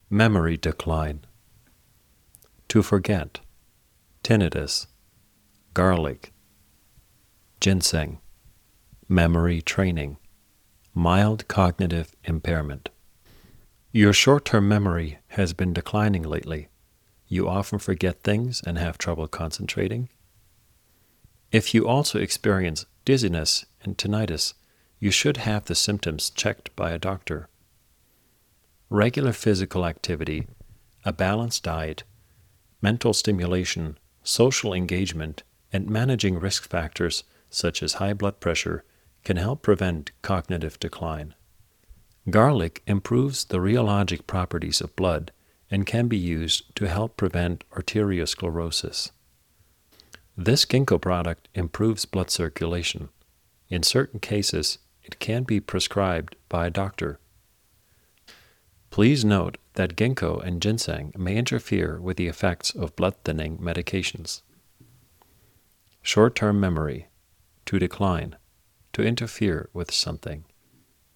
In dieser Englisch-Lerneinheit vermitteln wir Ihnen Wörter, die Sie im Beratungsgespräch verwenden können, wenn es um nachlassende Gedächtnisleistung geht. Hören Sie in unserer Audiodatei, wie die englischen Wörter ausgesprochen werden:...